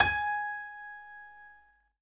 15 SY99 Piano G#5.wav